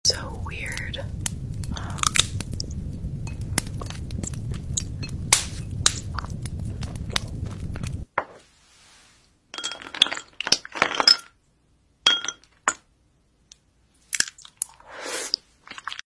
Just feel the tingles.